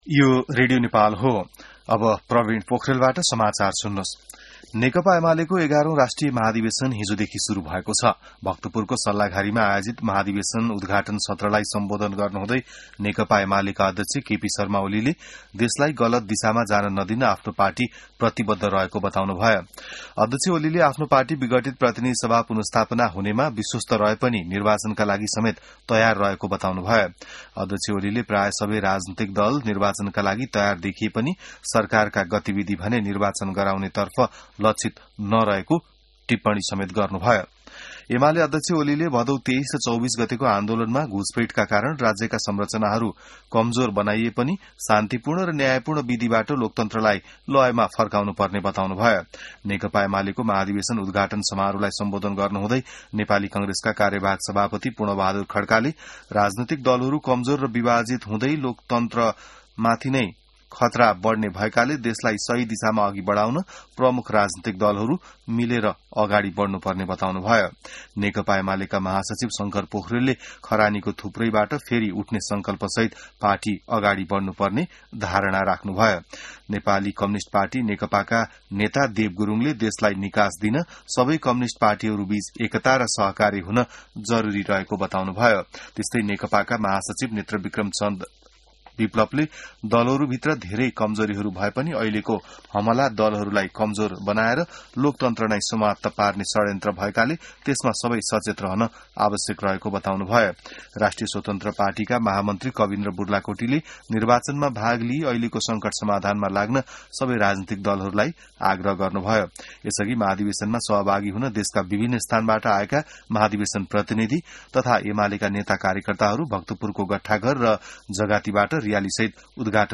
बिहान ६ बजेको नेपाली समाचार : २८ मंसिर , २०८२